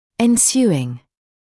[ɪn’sjuːɪŋ][ин’сйуːин]следующий, последующий; инговая форма от to ensue